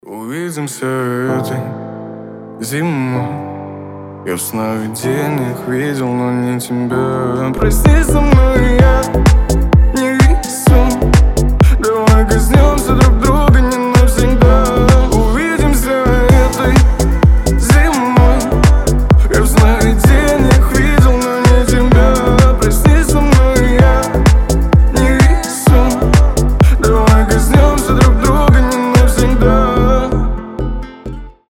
лирика